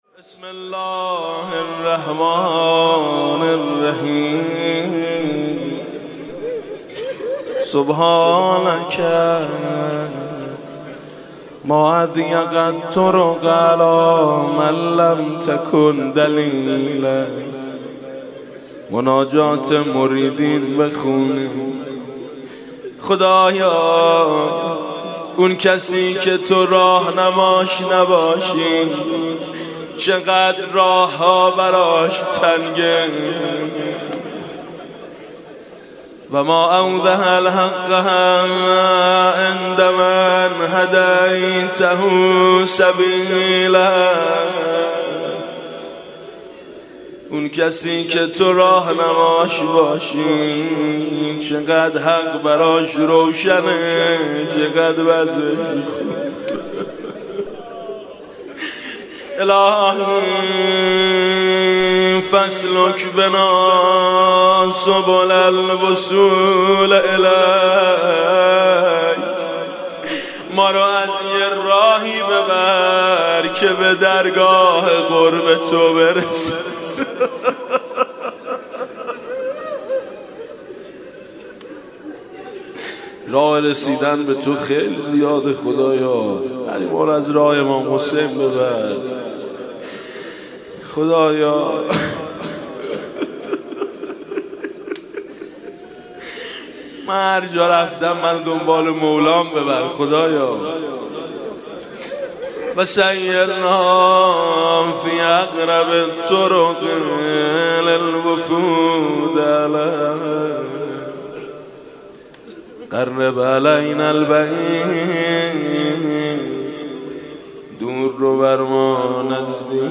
صوت/ "مناجات المریدین" با نوای میثم مطیعی